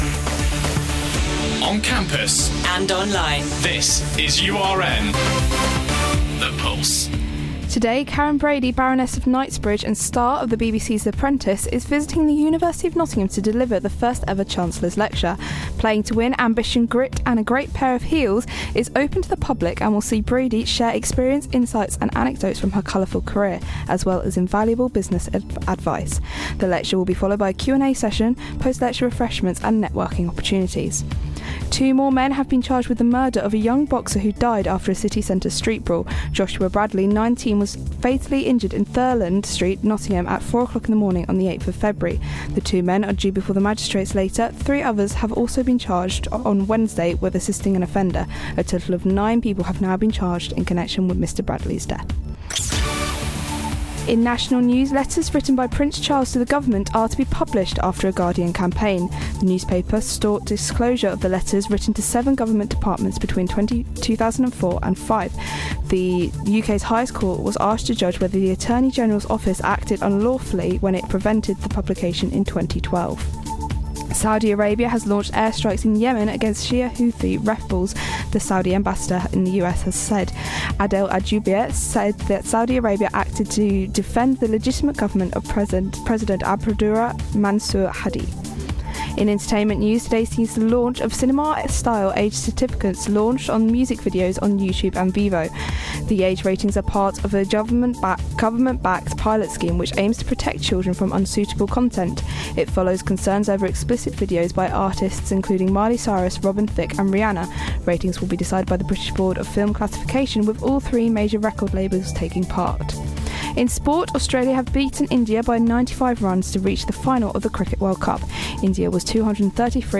Your latest headlines on URN